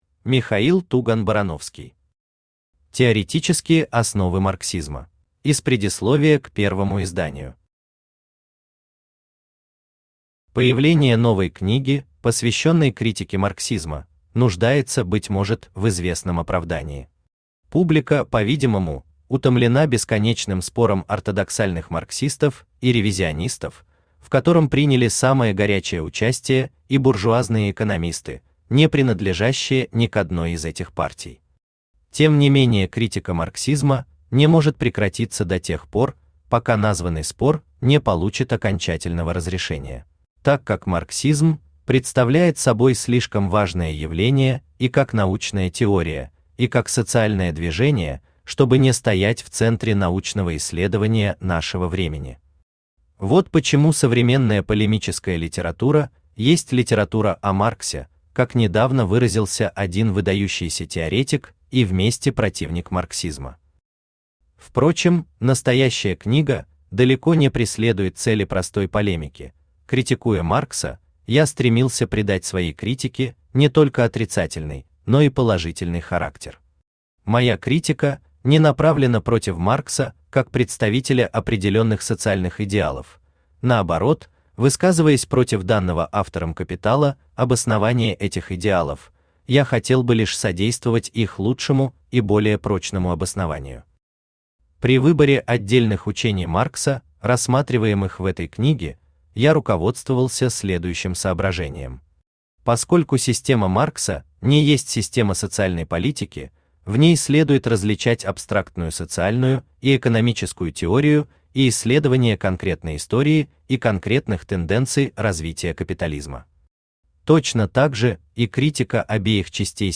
Аудиокнига Теоретические основы марксизма | Библиотека аудиокниг
Aудиокнига Теоретические основы марксизма Автор Михаил Иванович Туган-Барановский Читает аудиокнигу Авточтец ЛитРес.